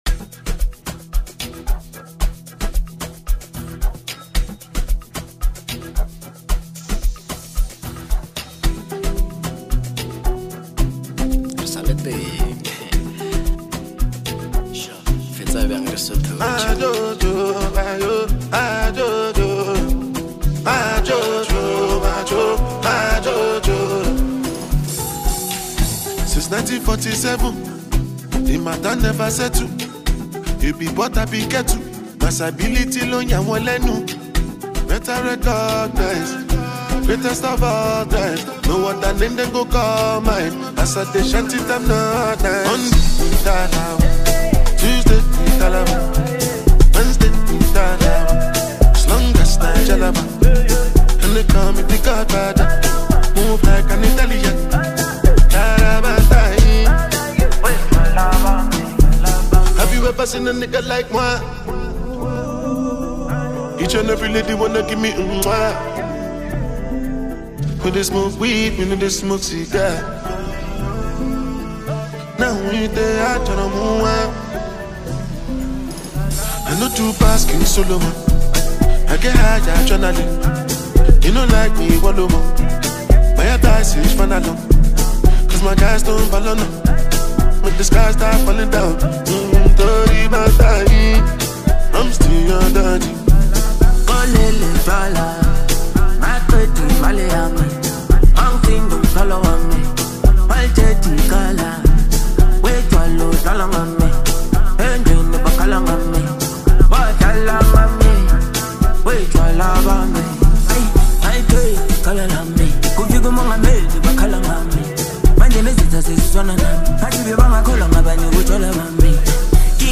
infectious rhythm